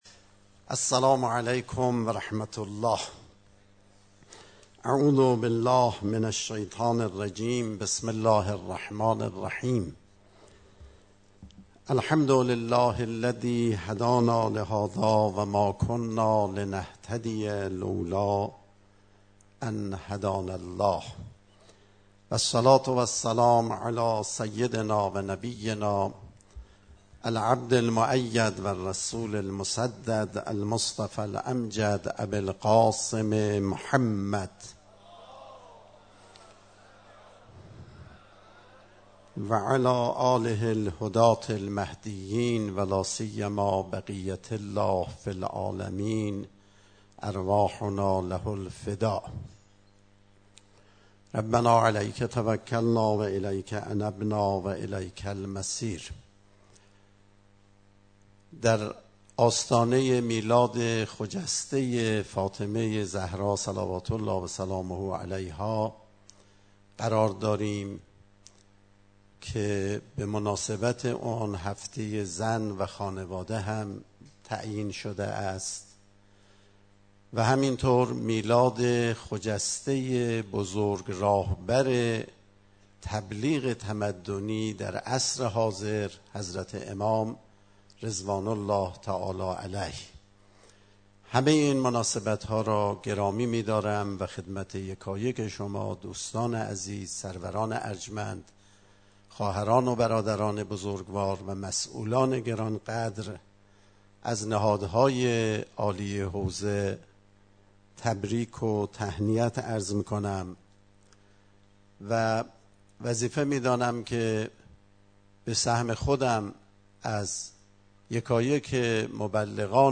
سخنرانی آیت الله اعرافی در آئین اختتامیه جشنواره ملی نوآوری‌های تبلیغی «جنات»